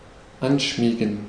Ääntäminen
IPA : /ˈnɛ.səl/